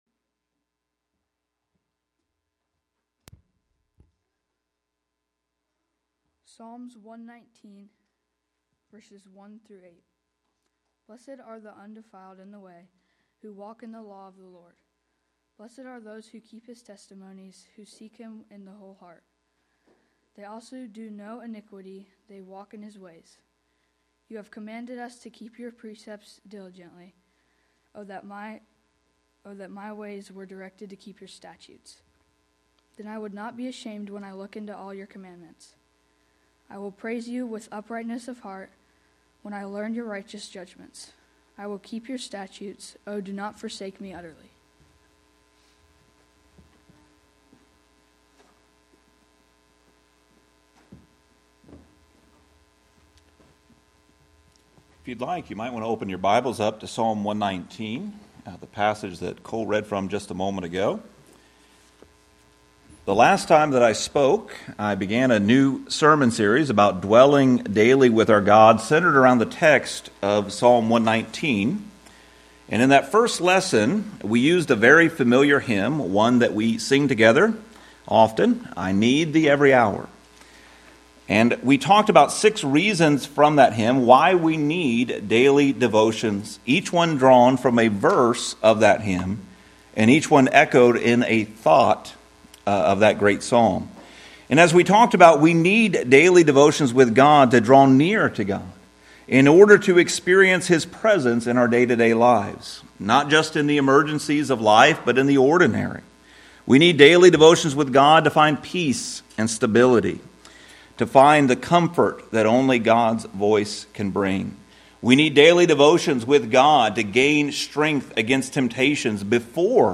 The sermon emphasizes that Scripture should not be viewed as a burden but as a path to joy, freedom, and relationship with God and that devotion to God requires the correct heart attitudes toward Scripture.